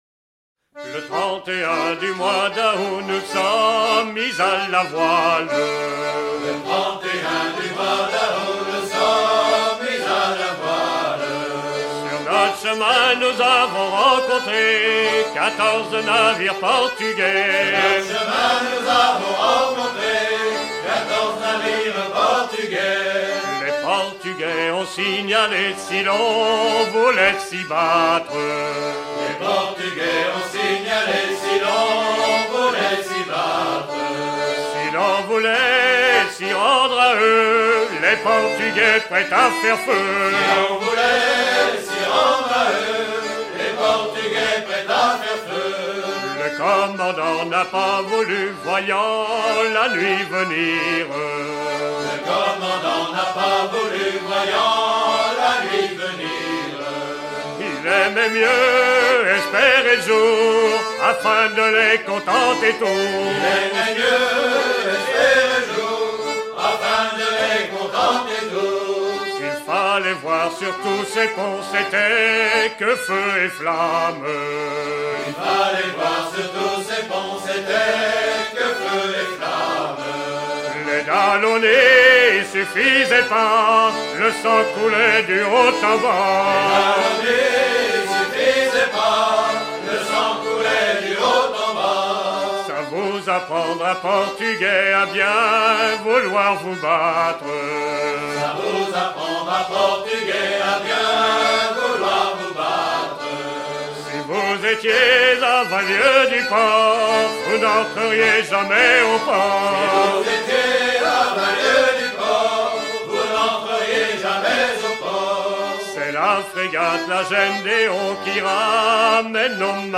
Chants de l'île d'Yeu, de Noirmoutier et de la côte vendéenne
Genre strophique